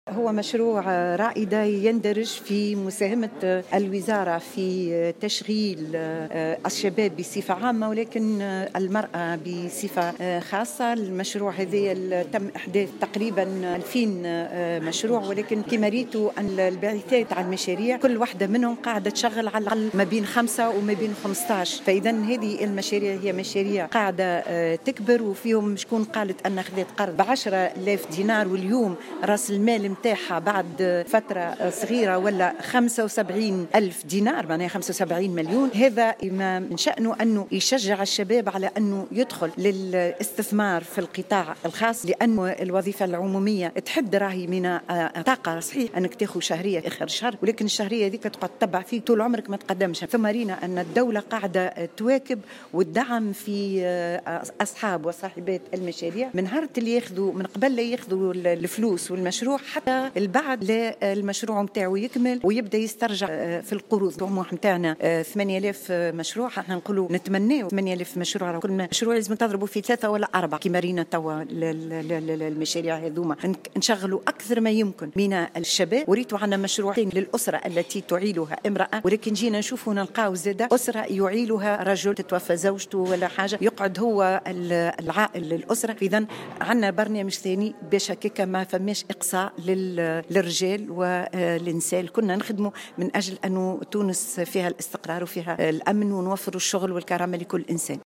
وأوضحت في تصريح لمراسل "الجوهرة أف أم" اليوم أن هذا البرنامج يقوم على توفير التمويلات الضرورية و مرافقة صاحبات المشاريع وتأطيرهم إلى حين استكمال المشاريع.